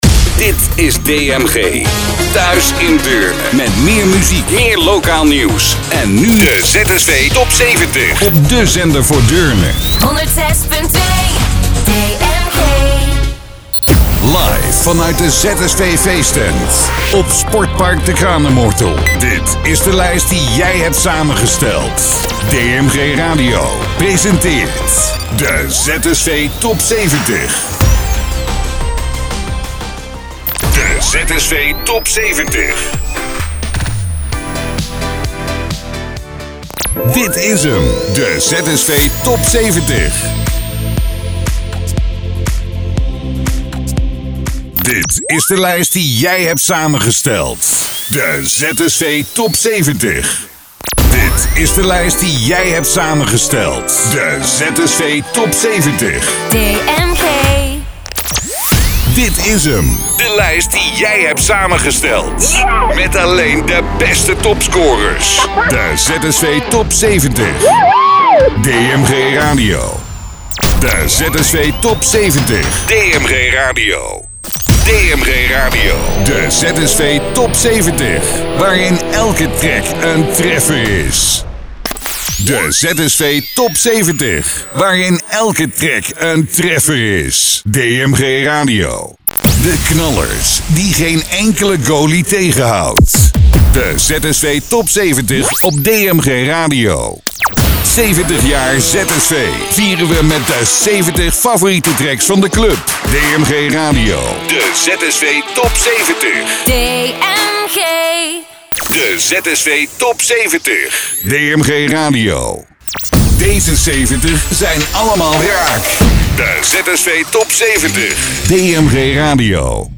Jinglepakket